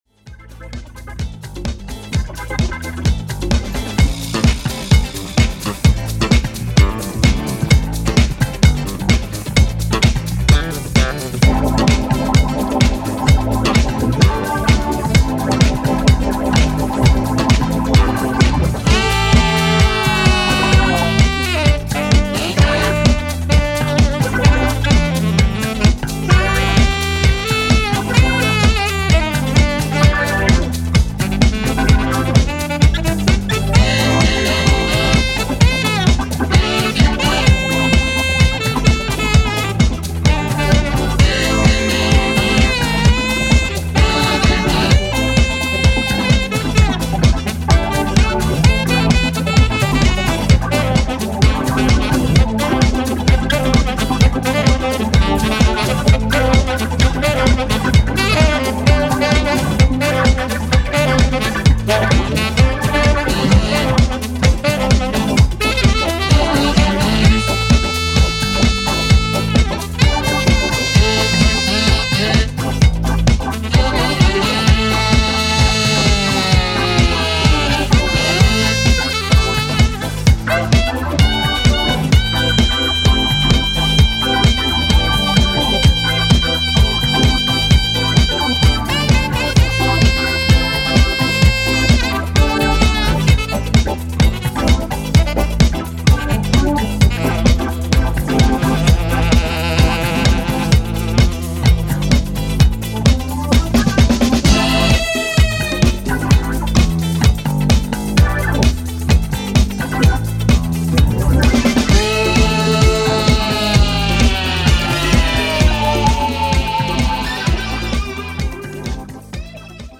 今回は、ラテンやジャズファンク要素を持ったご機嫌なブギー・エディットを4曲披露！
ジャンル(スタイル) DISCO / EDITS